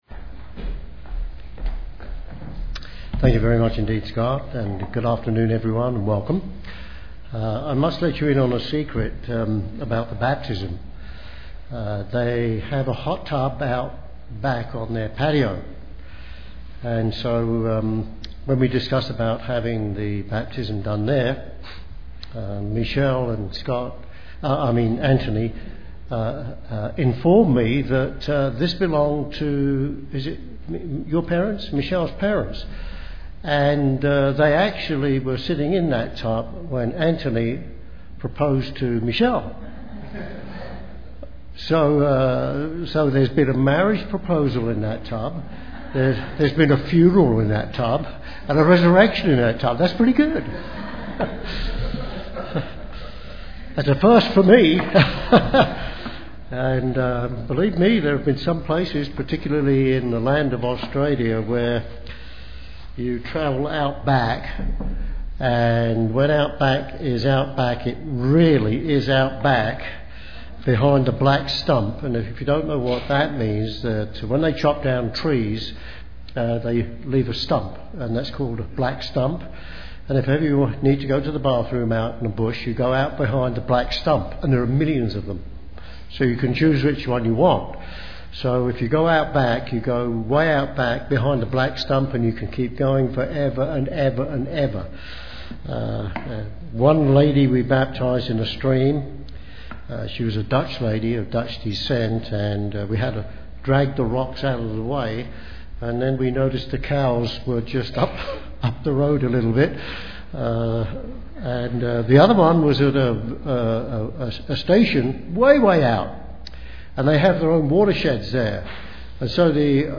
This sermon shows the difference between the two ideas usually tied together.